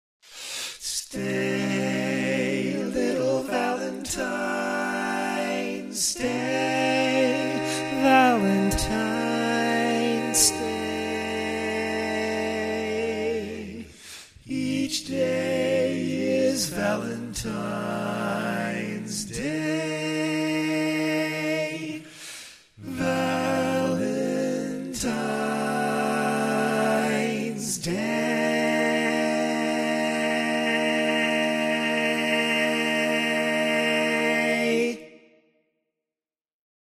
Key written in: B Minor
Type: Barbershop